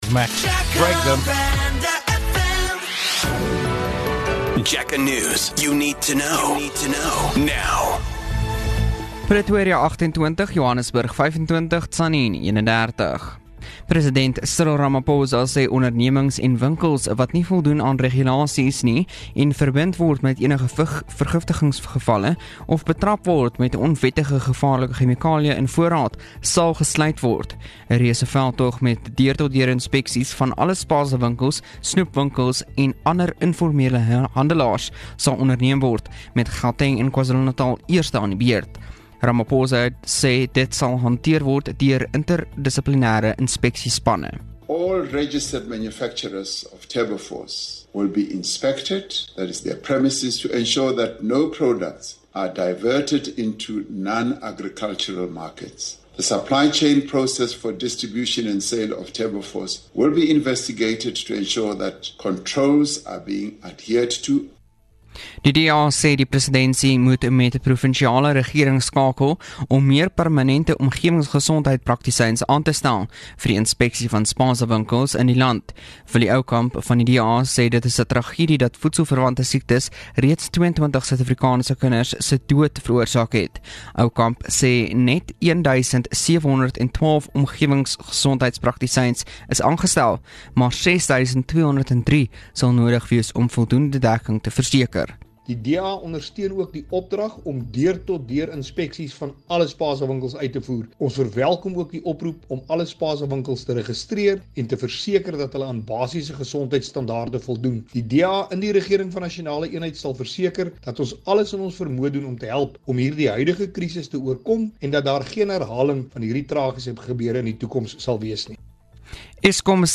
1 JacarandaFM News @ 11H00 4:30 Play Pause 1h ago 4:30 Play Pause Later Afspelen Later Afspelen Lijsten Vind ik leuk Leuk 4:30 Here's your latest Jacaranda FM News bulletin.